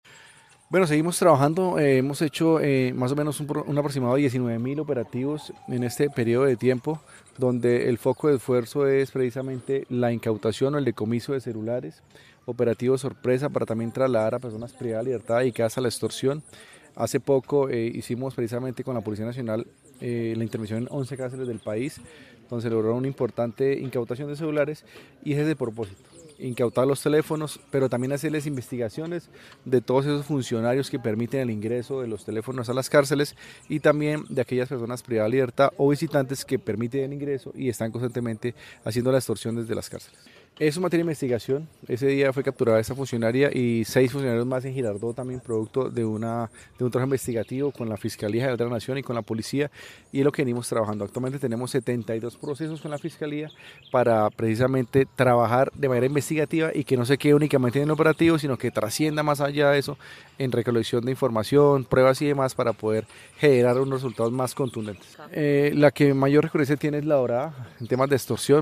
Así lo manifestó desde el Quindío, el teniente Coronel Daniel Gutiérrez, director del Instituto Nacional Penitenciario de Colombia, Inpec
teniente Coronel Daniel Gutiérrez, director del Inpec